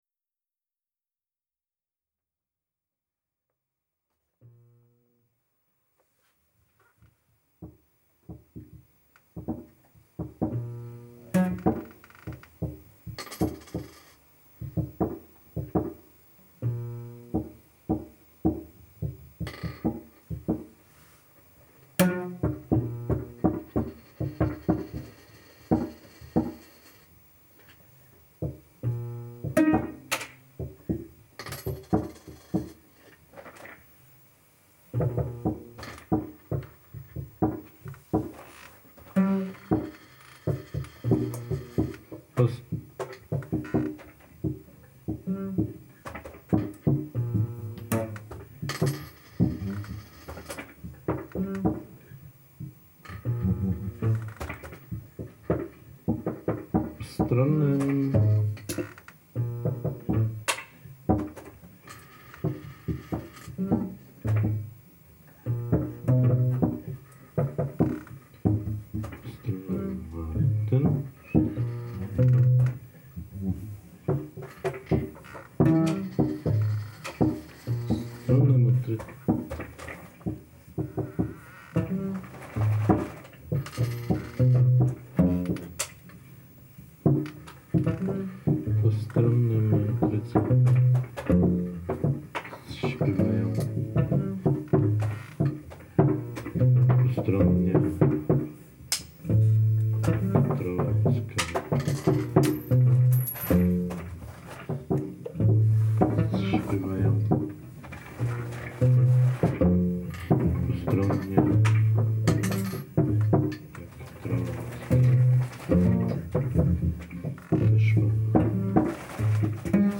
Just rehearsal – part 1
justrehearsal-02-sept22.mp3